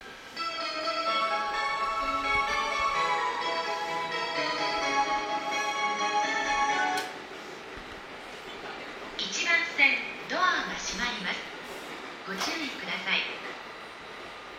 浦和駅１番線発車メロディー